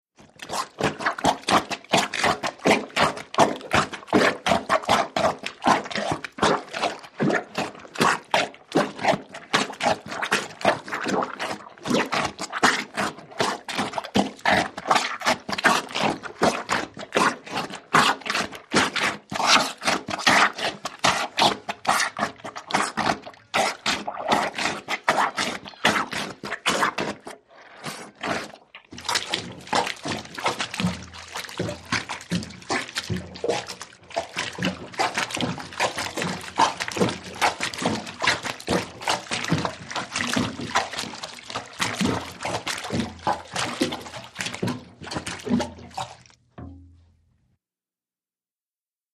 HOUSEHOLD SUCTION: INT: Rhythmic suction plumbers helper on laundry.